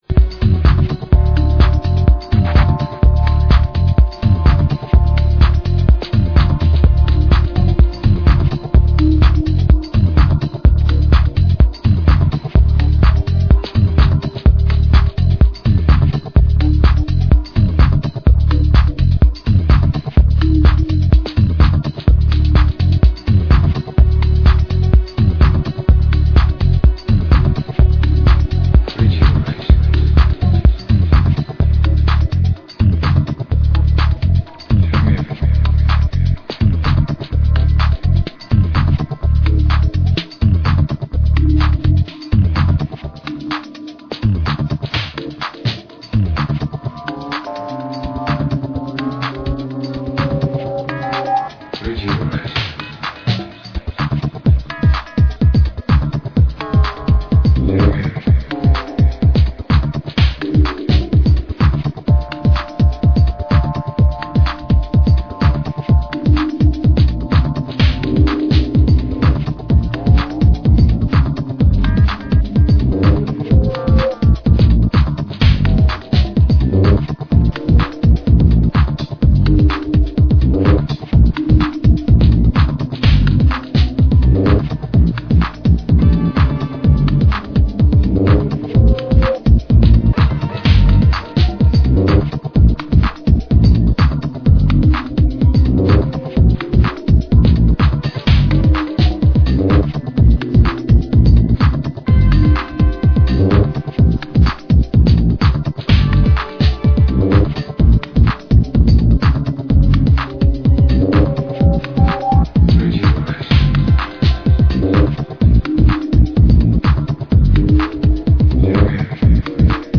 Romanian duo